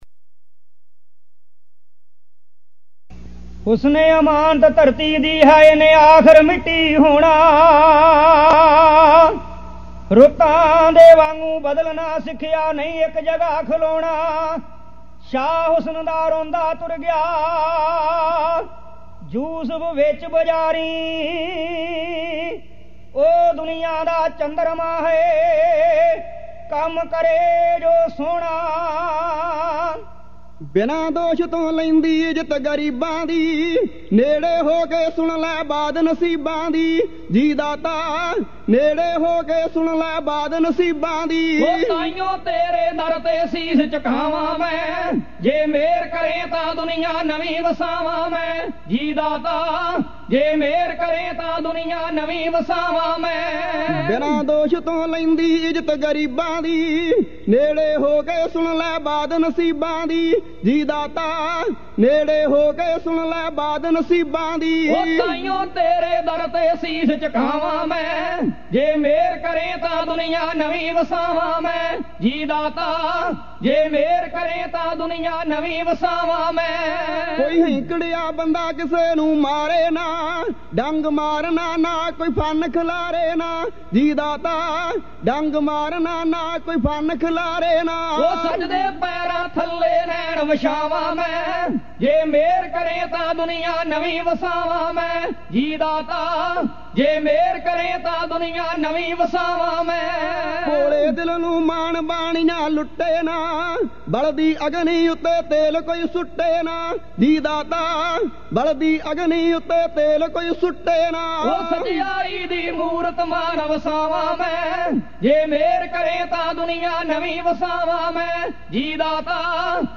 Kavishri Album Info